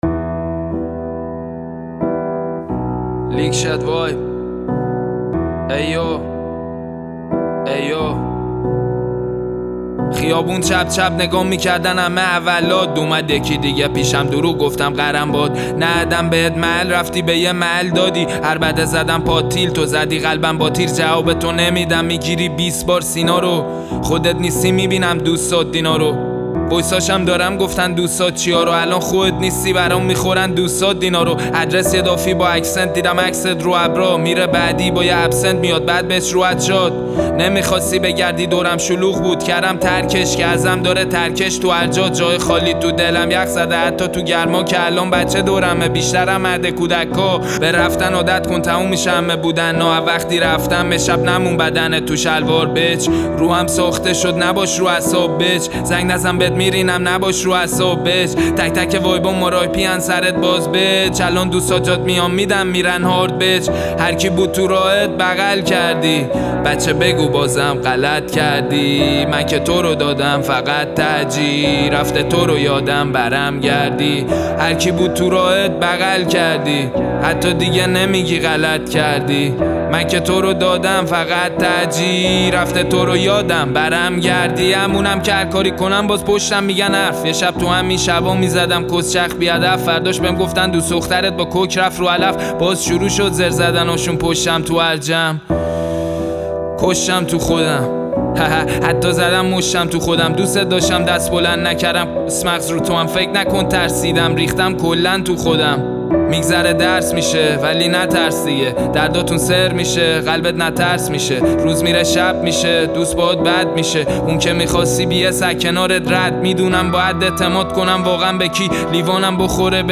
Rap
Piano Version